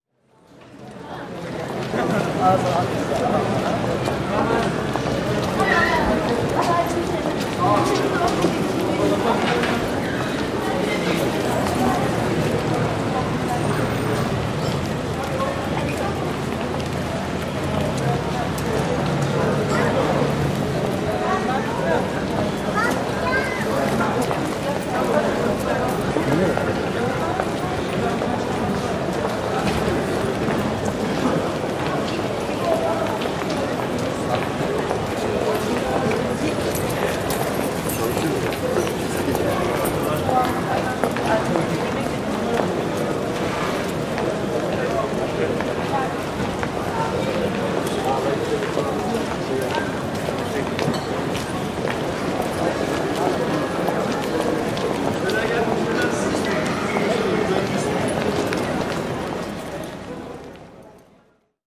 Звук улицы в Турции (гуляем пешком) (01:02)
Шаги по турецким улочкам и их звучание